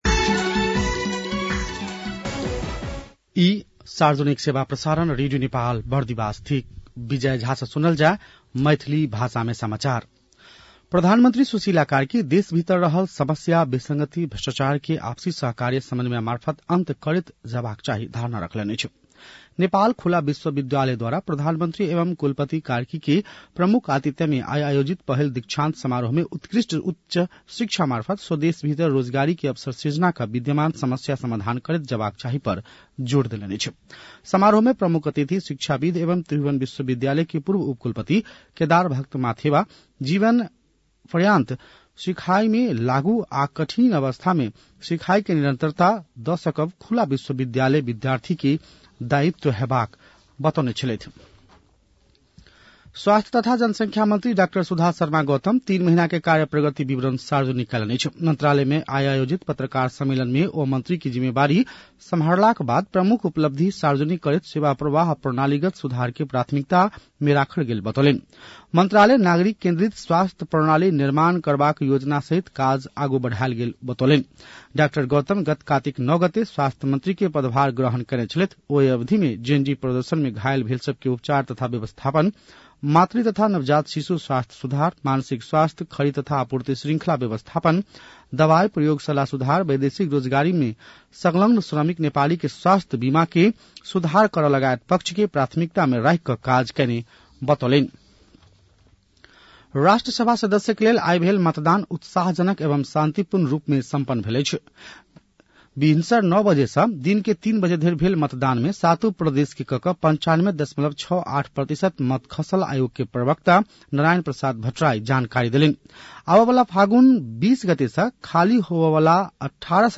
मैथिली भाषामा समाचार : ११ माघ , २०८२
Maithali-news-10-11.mp3